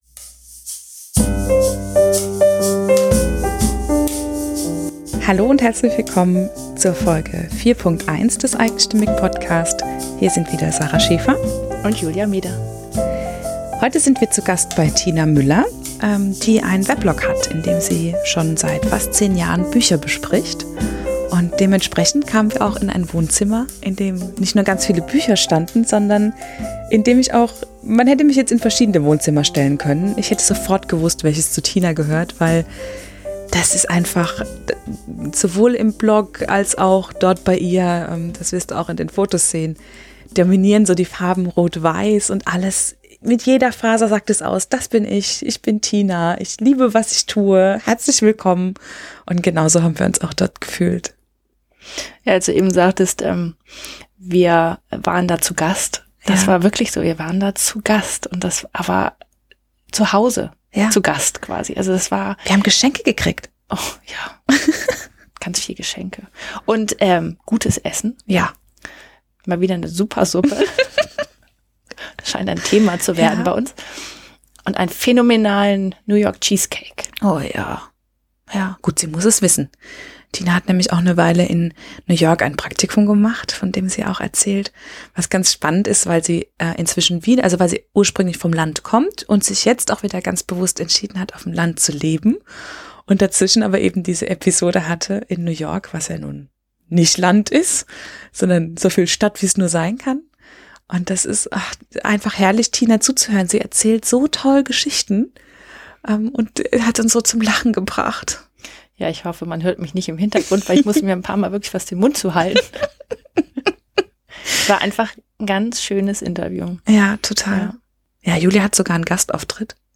Interview eigenstimmig